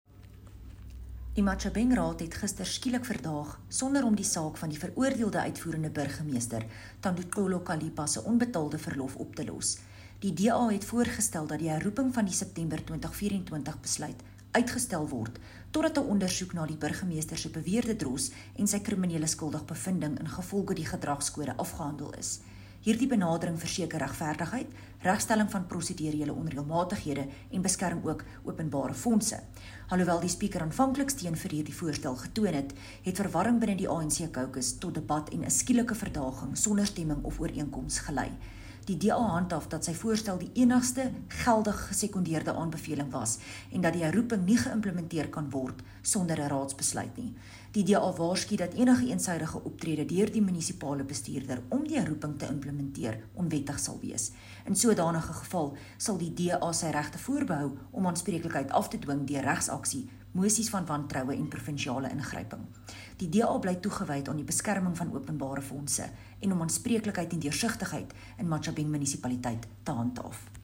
Afrikaans soundbites by Cllr René Steyn and